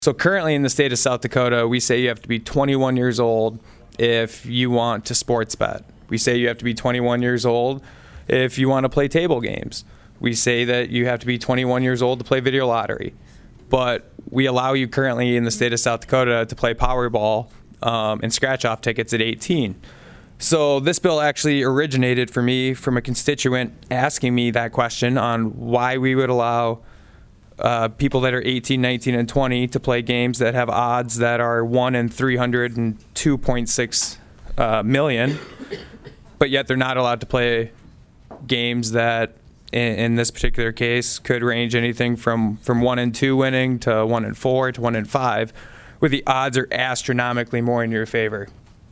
PIERRE, S.D.(HubCityRadio)- The South Dakota Senate Commerce & Energy Committee heard testimony on SB203 on Thursday.
Prime sponsor of the bill is Senator Michael Rohl of Aberdeen who is looking to raise the age limit from 18 to 21.